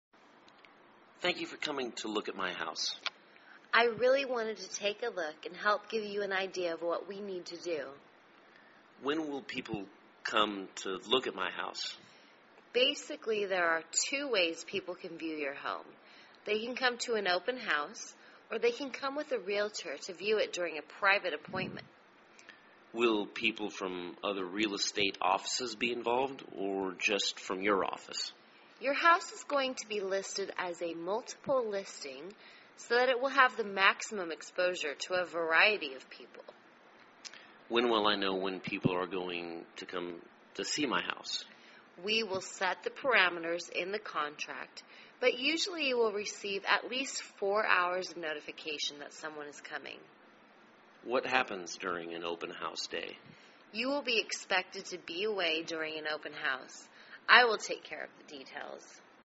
卖房英语对话-Marketing Your House(1) 听力文件下载—在线英语听力室